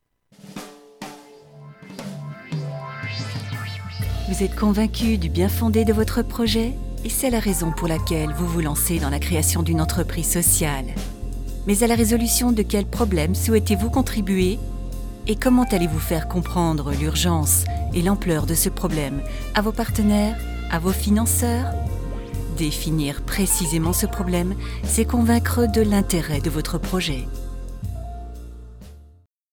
Voix médium, séduisante, s'adaptant à tous vos projets, Home studio Pro, travail sérieux, livraison rapide.
Sprechprobe: eLearning (Muttersprache):
French native female voice, warm, elegant, seduisant, accent.